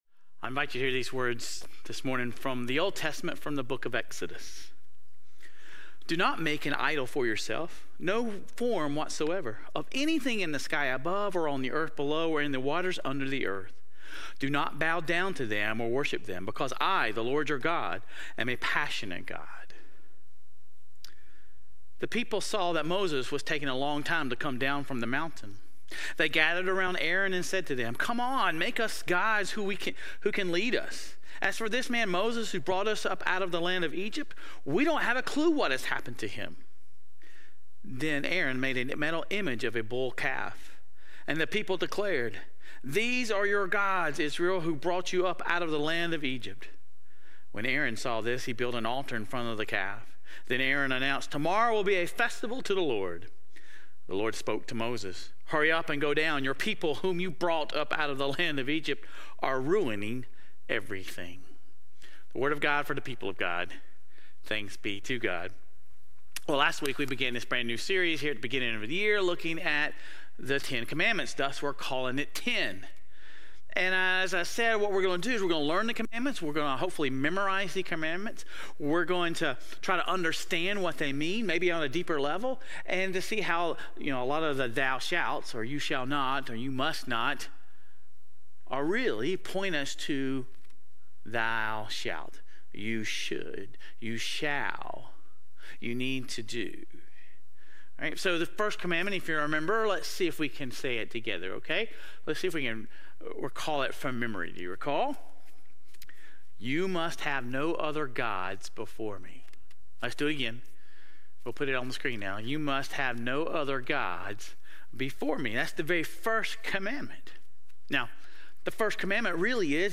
Sermon Reflections: The Israelites created a golden calf as an idol.